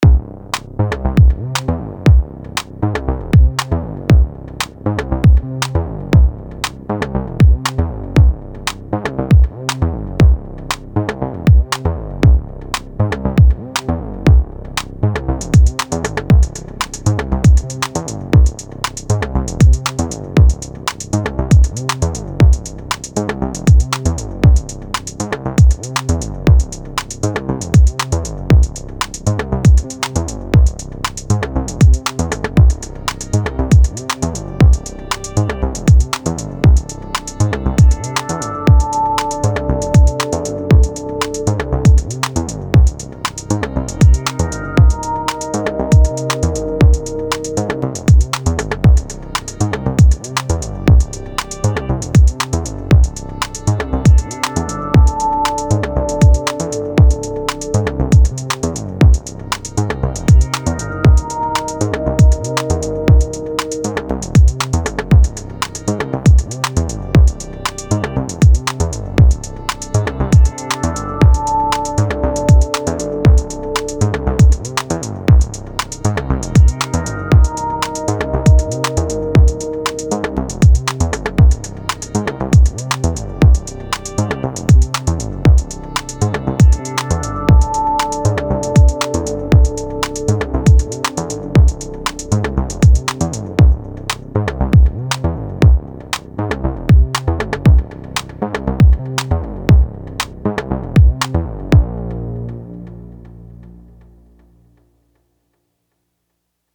A4 solo quickjam.